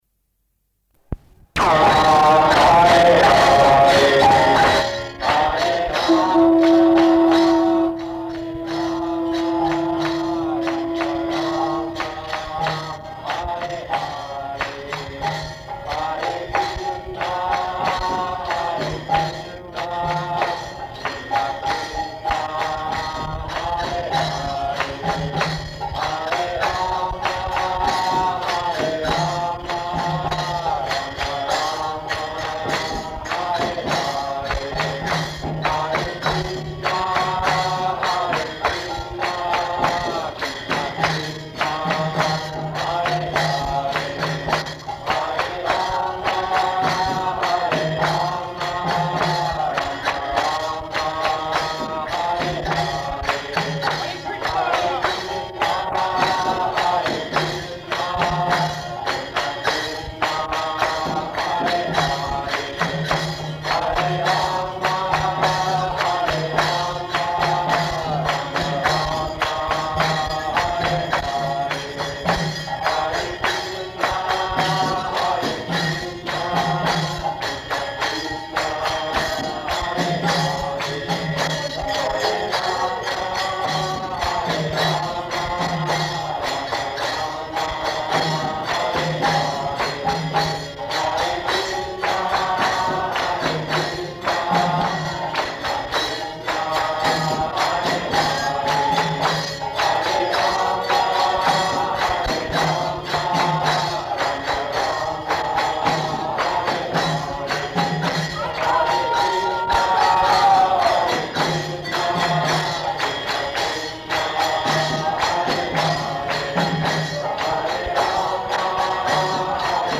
Lecture
Type: Lectures and Addresses
Location: San Francisco
[ kīrtana ]